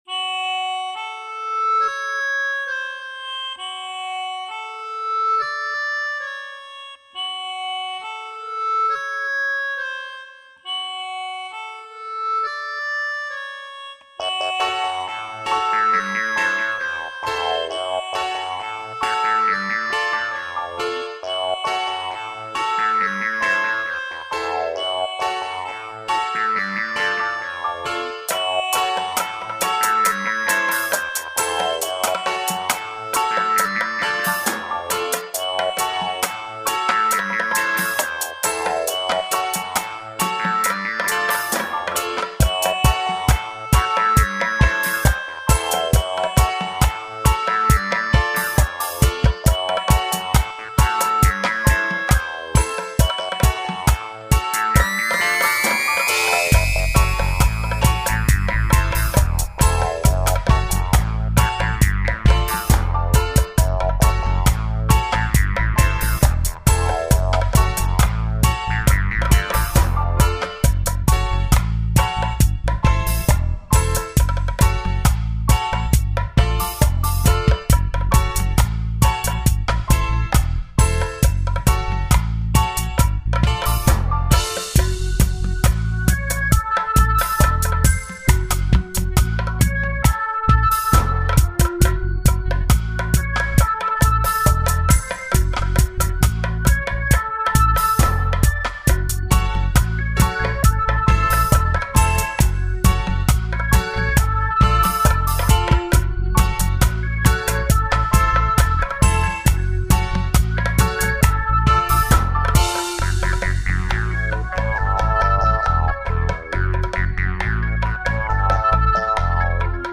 EXTENDED MIX WITH FULL DUB VERSION